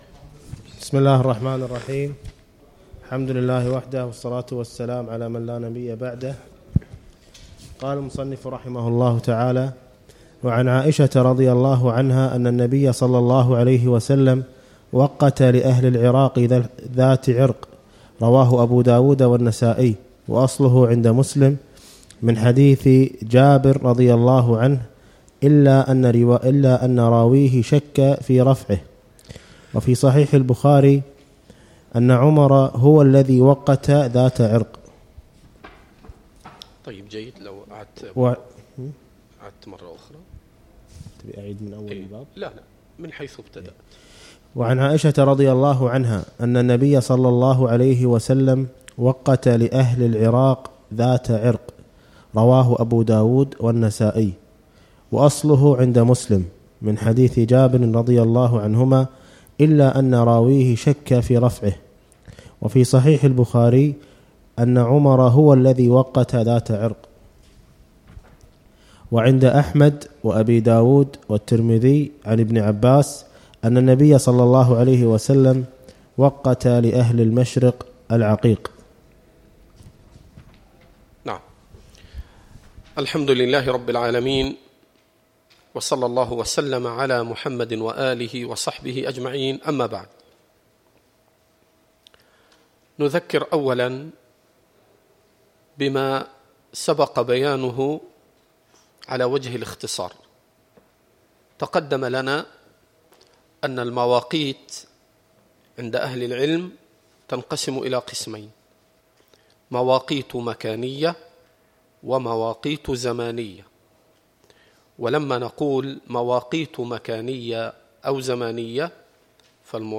الدرس الخامس - شرح كتاب الحج من بلوغ المرام
الدروس والمحاضرات